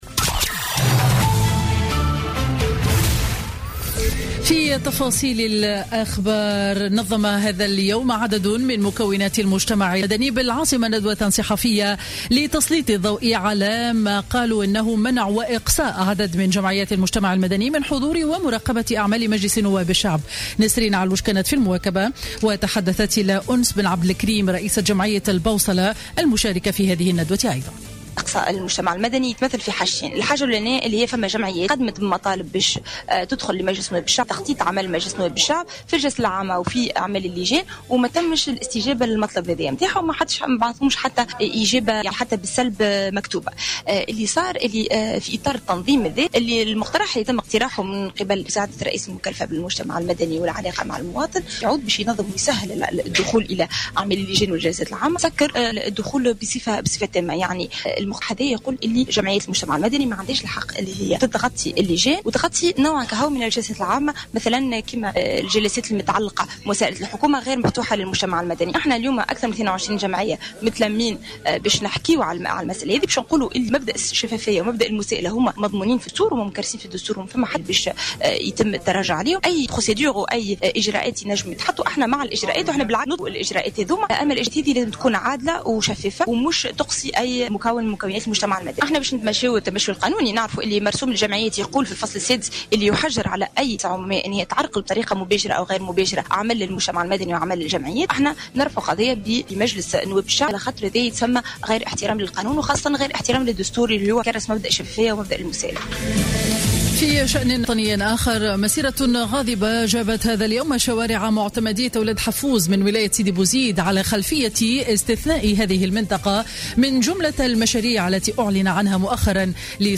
نشرة أخبار منتصف النهار ليوم الإثنين 26 أكتوبر 2015